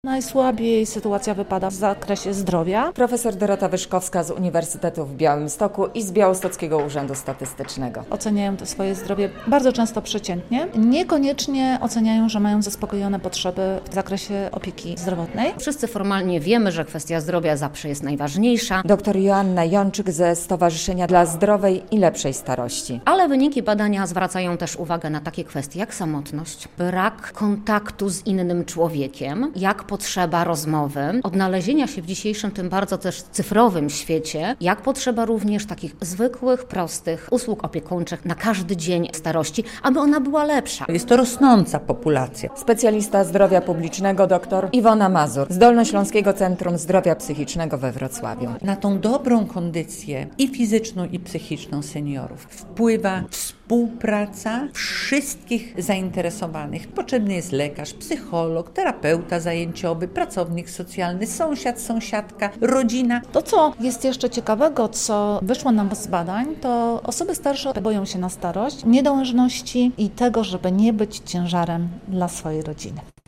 Na białostockim sympozjum eksperci z różnych instytucji publicznych zastanawiali się co zrobić, by życie seniorów było lepsze.
relacja